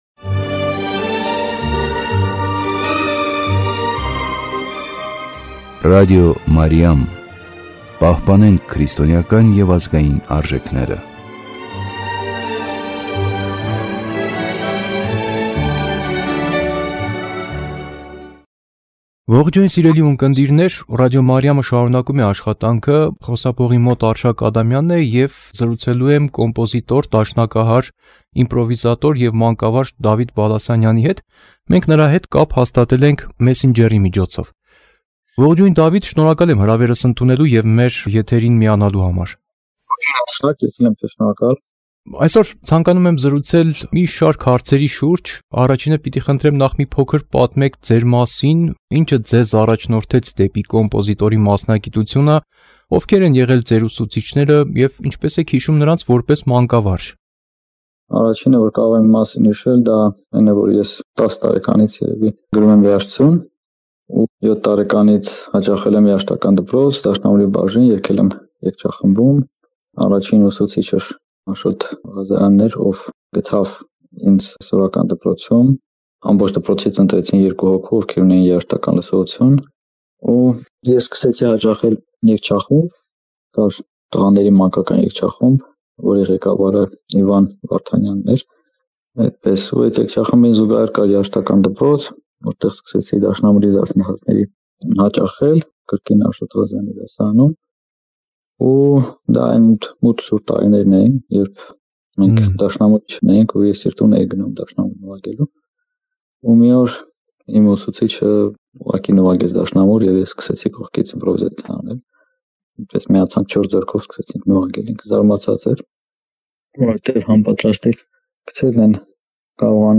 Այս և այլ հարցերի շուրջ զրուցում ենք կոմպոզիտոր, դաշնակահար, իմպրովիզատոր և մանկավարժ